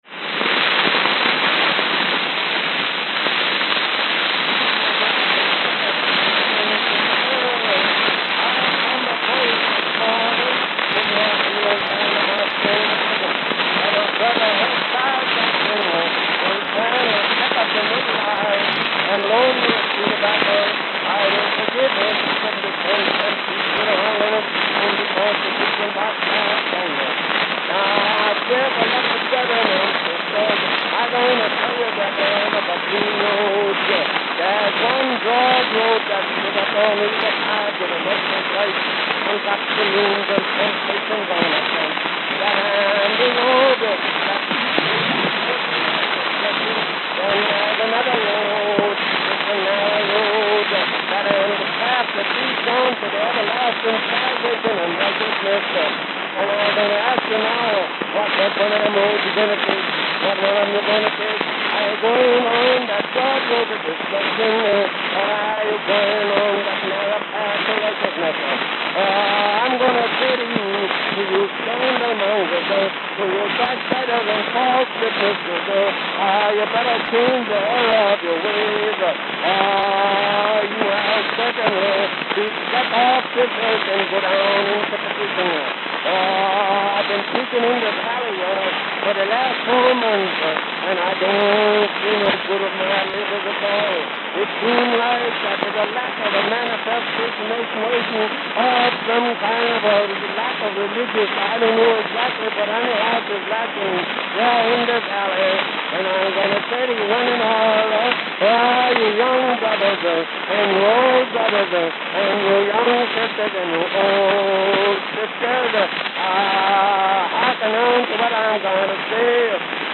Note: It's not your sound system. The record really does sound like this.
Note the speed problem the engineer had at 0:44.
It may also sound better (if you can call it that) without the final EQ: No-Equalization Version